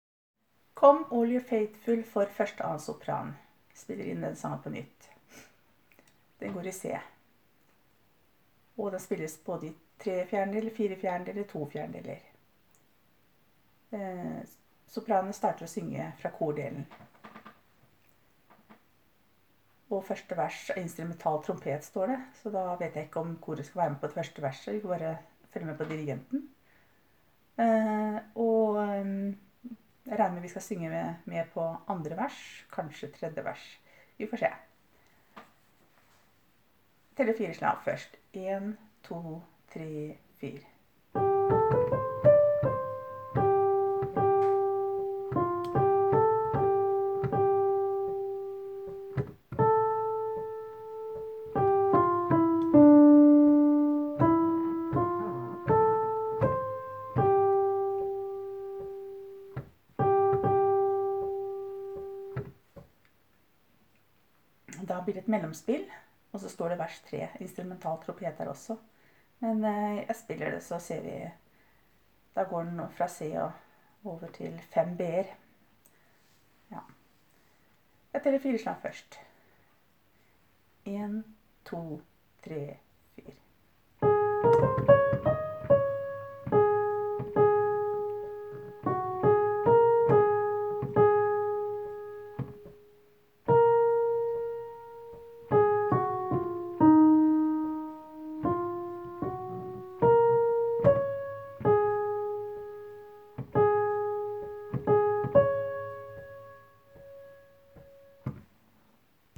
Jul 2017 Sopraner (begge konserter)
Come-all-ye-faithfull-1-og-2-Sopran-rettet-opp.m4a